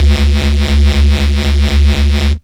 bass m 1.96.wav